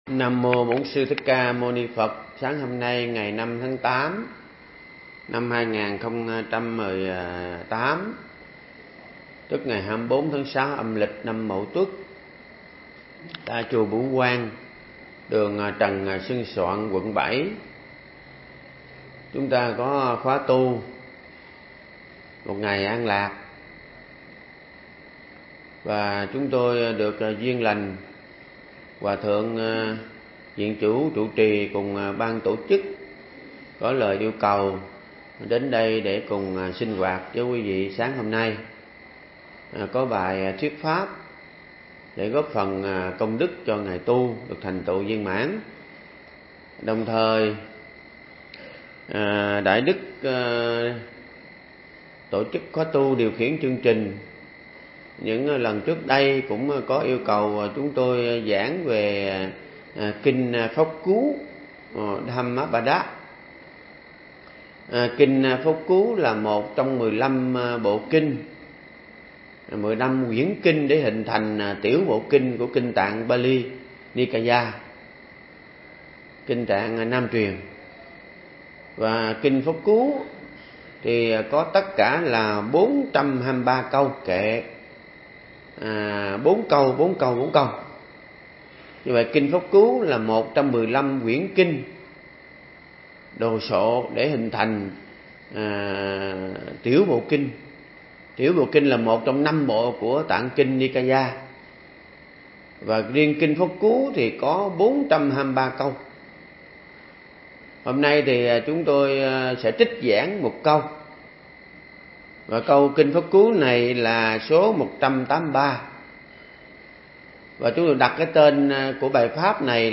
Mp3 Thuyết Giảng Đức Phật dạy gì